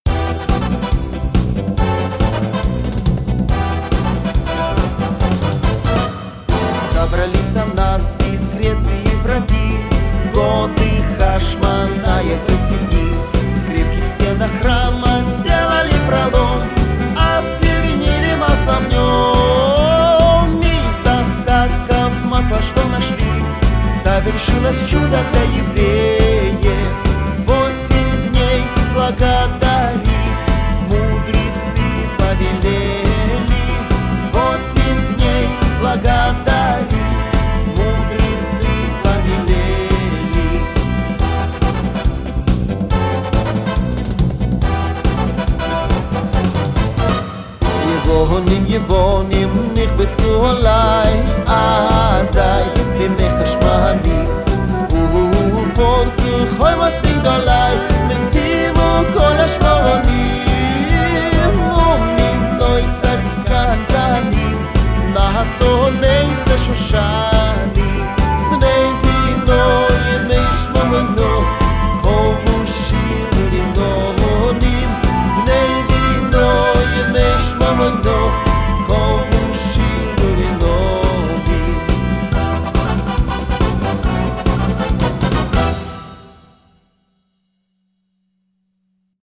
Ханукальные песни – Йеваним, йеваним – с переводом
Ханукальные песни добавят веселое и праздничное настроение!